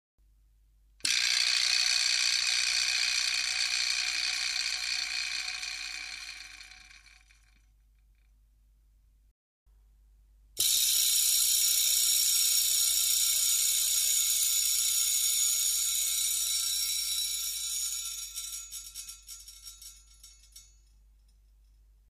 ビブラスタンド
自転車のスタンドをつかっています　木の部分をたたくと　金属部分の仕掛で音がでます